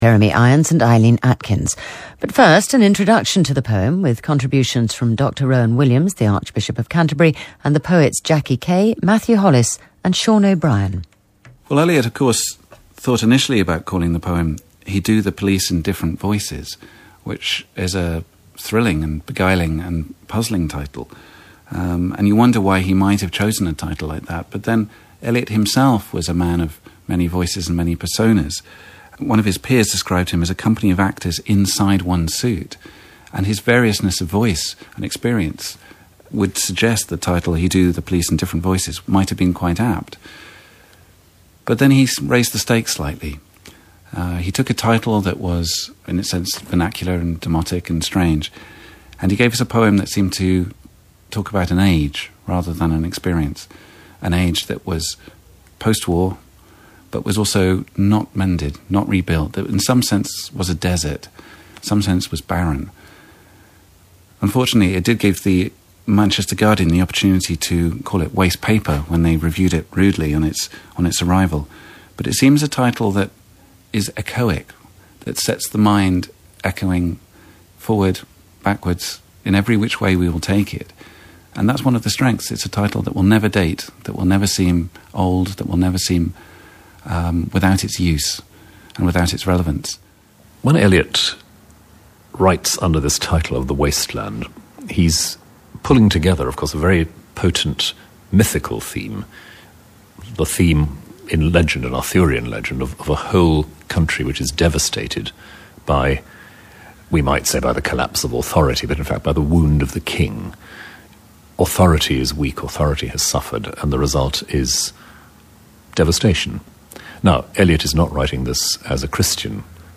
Complete audio of The Waste Land, read by Jeremy Irons and Eileen Atkins…
Their measured delivery, never overdone, captures the poem’s bleak emotional landscape, breathing life into its panoramic sweep and mundane detail, with Atkins chillingly perfect in Death by Water .